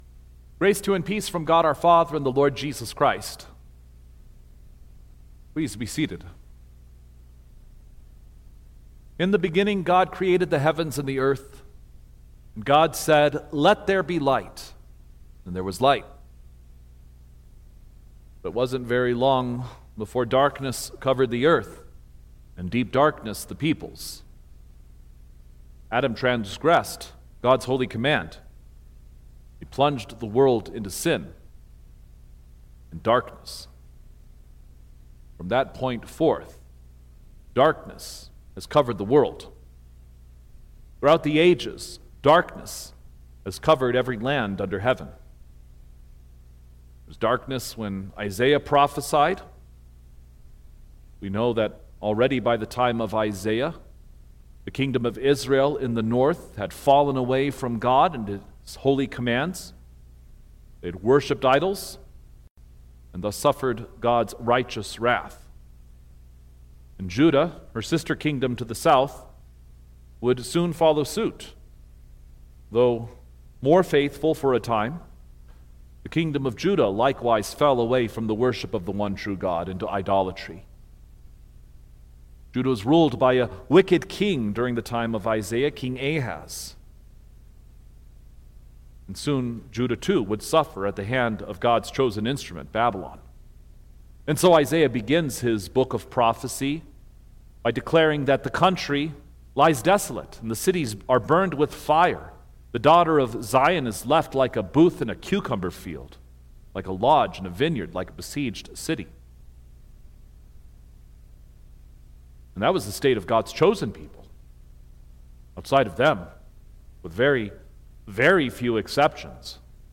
January-7_2026_The-Epiphany-of-Our-Lord_Sermon-Recording-Stereo.mp3